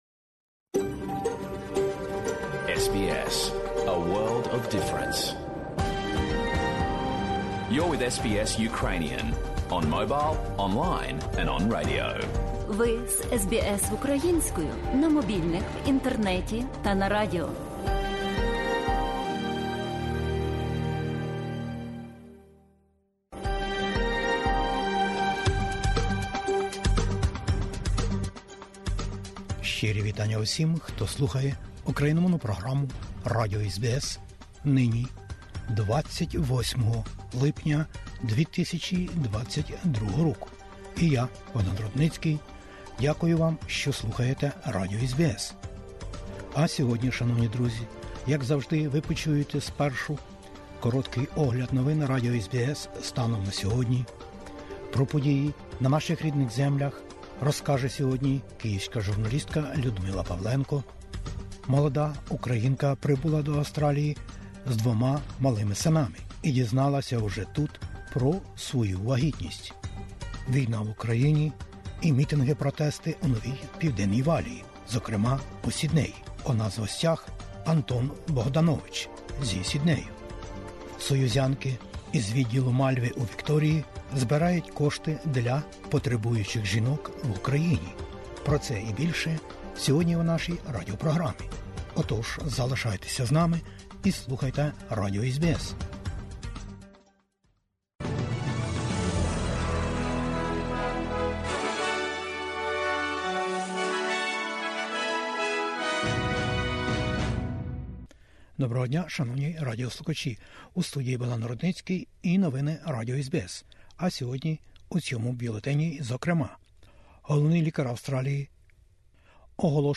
Головні акценти україномовної радіопрограми за минулий тиждень. Зокрема, серед іншого: Австралія багатокультурна, війна в Україні, інтерв'ю та пісня, що викликала бурхливі оплески на концерті братніх народів у найбільшому театрі Австралії...